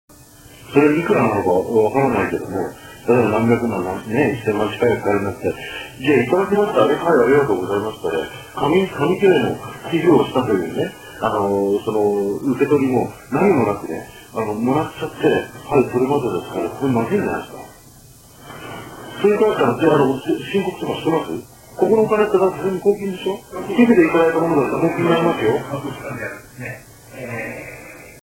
以下が修正された波形で、黄色で輝いている部分が音声会話、周辺で黄色を囲む部分が除去されたノイズ成分です。 この波形は、30の工程を経た明瞭化技術を駆使して、音声会話をより聴きやすい形に修正されたものです。
★ （赤）のノイズ成分を除去すると音声データは明瞭化される。